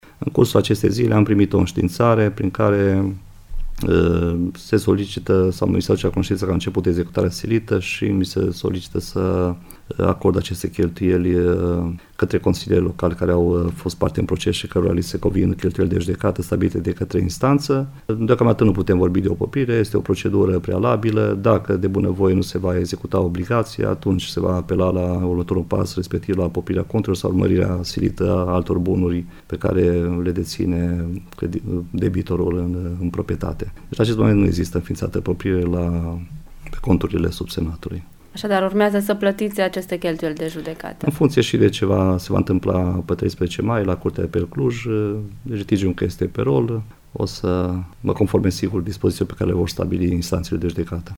Invitat la Radio Sighet, primarul Vasile Moldovan spune că în decursul zilei de vineri, 6 mai, a primit o înștiințare despre obligativitatea plății acestor cheltuieli, în cuantum de 6.500 lei, nicidecum despre poprire, așa cum se afirmă în presa locală:
primar-sighet.mp3